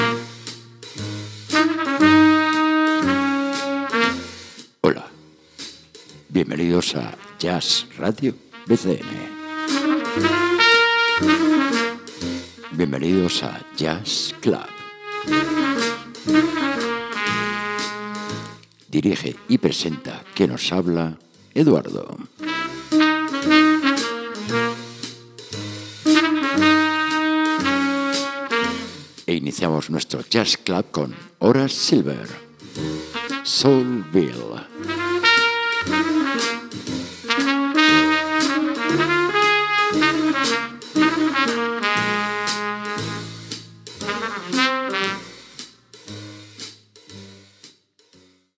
Presentació i tema musical
Musical